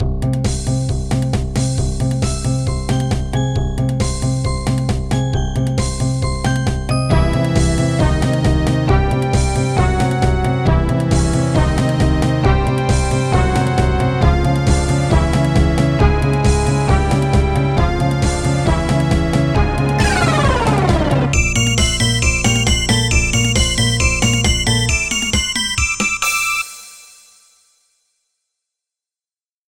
The second opening theme
Converted from .mid to .ogg